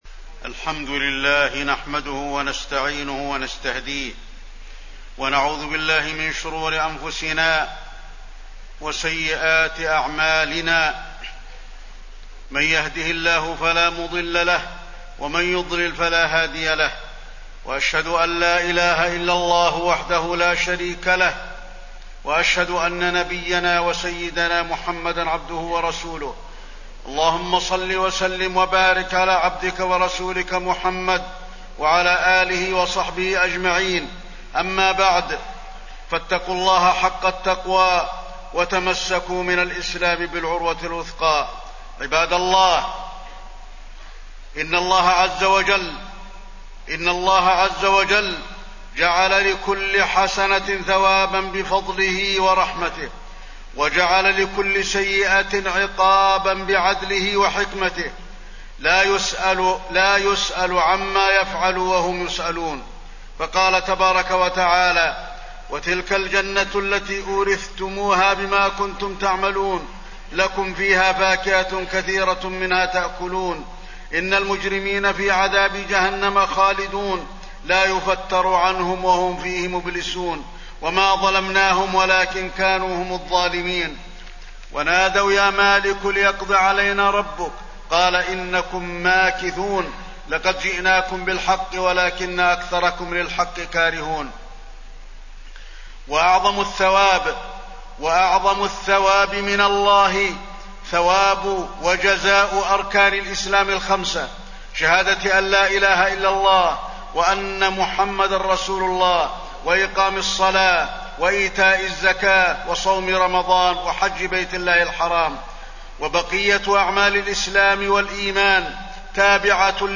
تاريخ النشر ١٨ ذو القعدة ١٤٣٠ هـ المكان: المسجد النبوي الشيخ: فضيلة الشيخ د. علي بن عبدالرحمن الحذيفي فضيلة الشيخ د. علي بن عبدالرحمن الحذيفي مقاصد الحج